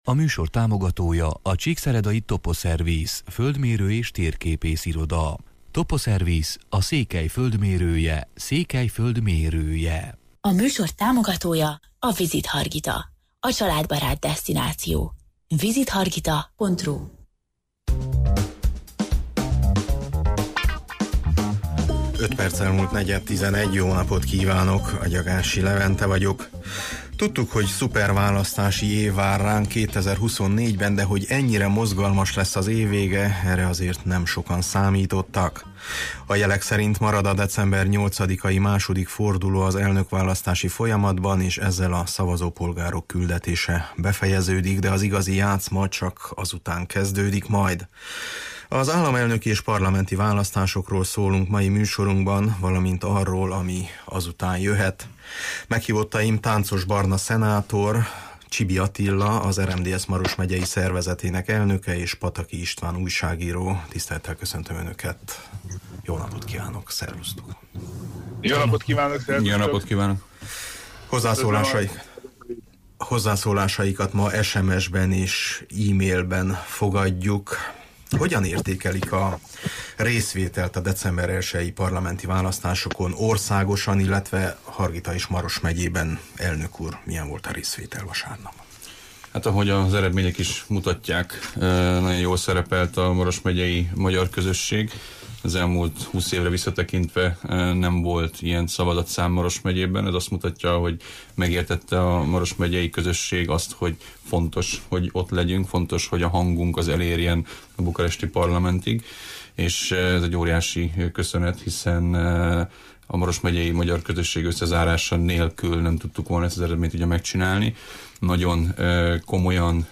Az államelnöki és parlamenti választásokról szólunk mai műsorunkban, valamint arról, ami azután jöhet. Meghívottaim: Tánczos Barna szenátor